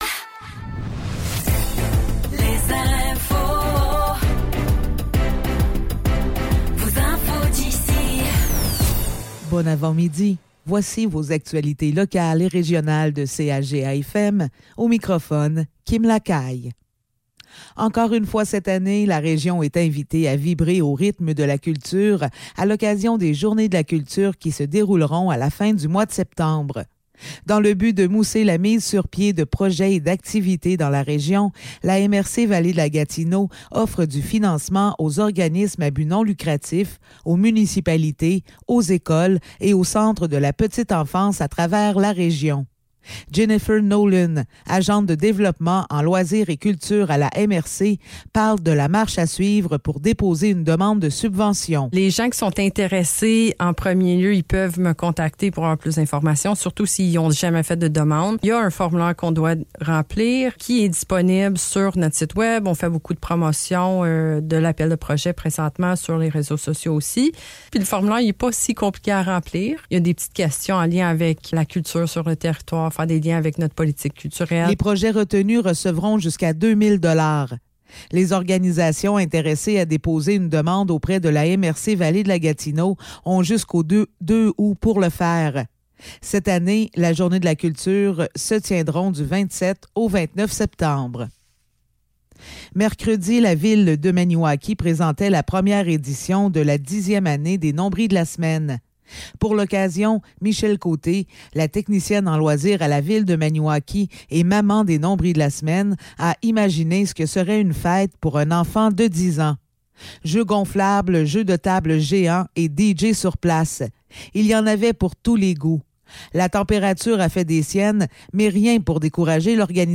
Nouvelles locales - 5 juillet 2024 - 10 h